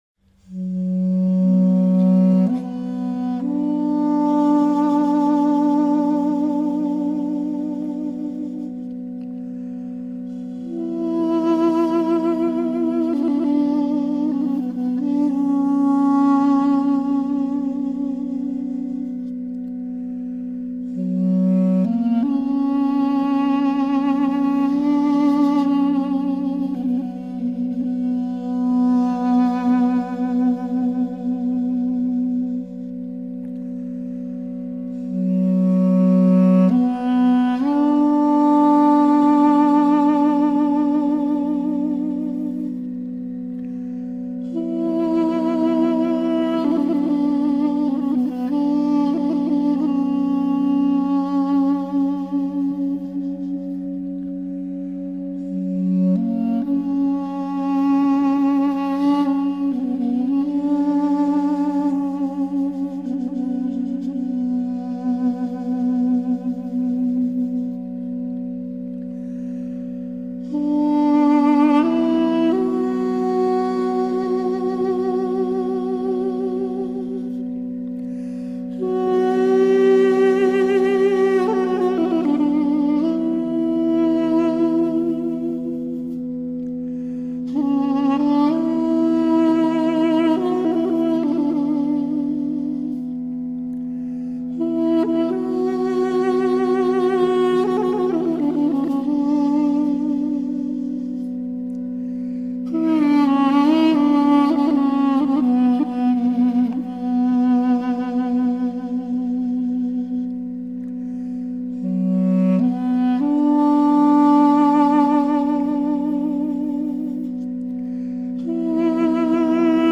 这个世界上有种古老的乐器叫做duduk。
它是一种双簧风鸣乐器。
duduk其声音苍凉，孤独，带着浓浓的悲剧色彩。